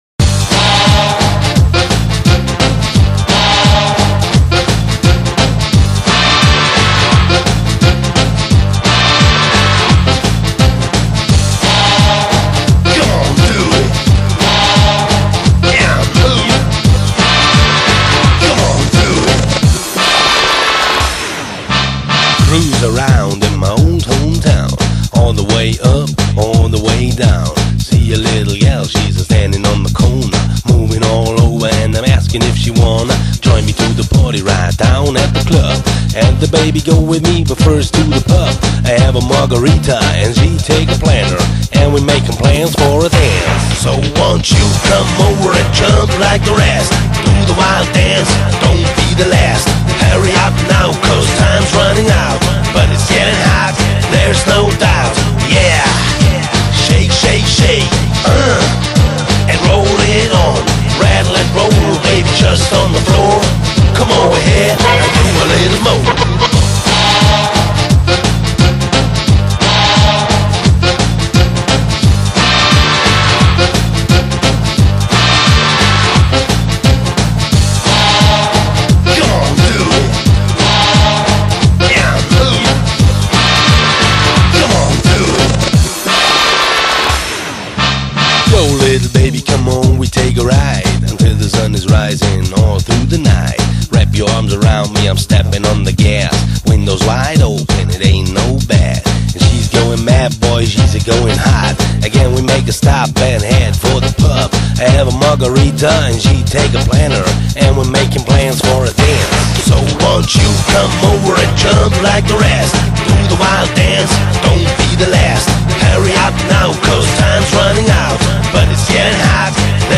【音乐类型】：纯音乐专辑5CD
门金曲，旋律性强而富有动感，散发着无穷的时尚气息。